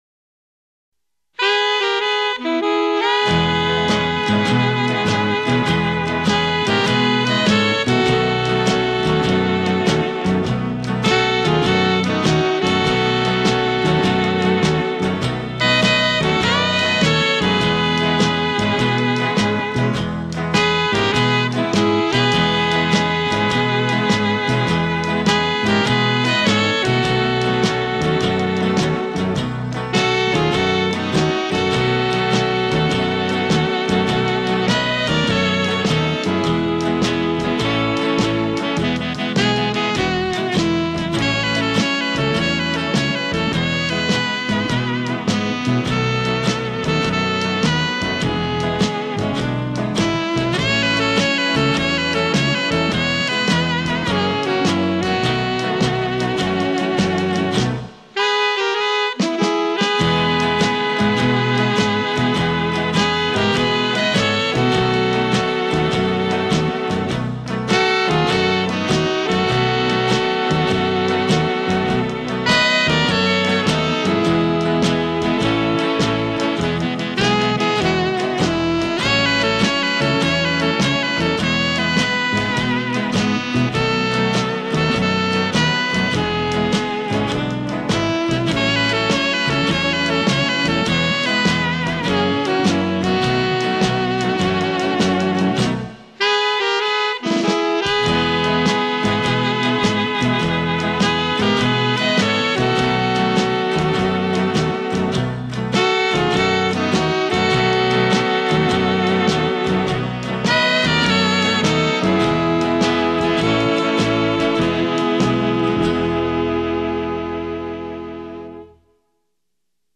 ● 录音制式：ADD STEREO